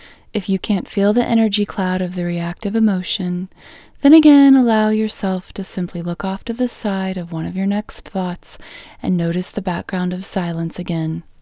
LOCATE OUT English Female 31